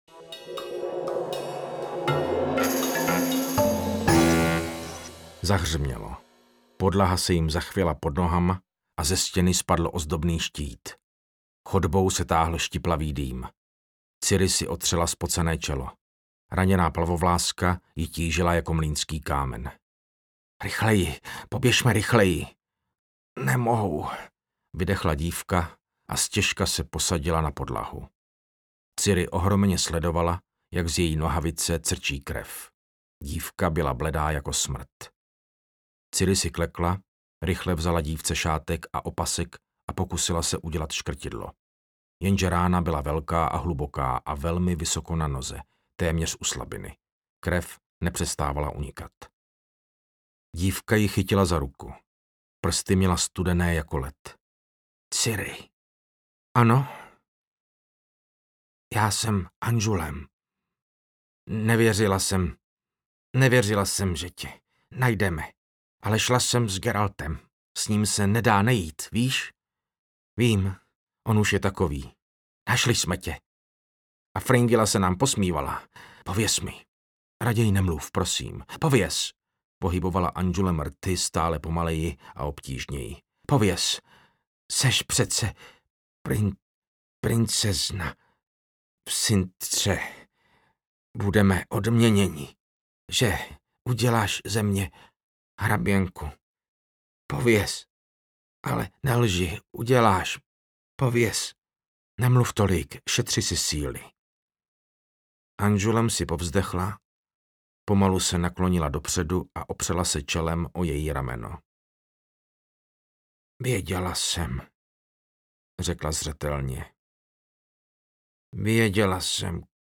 Paní jezera audiokniha
Ukázka z knihy
• InterpretMartin Finger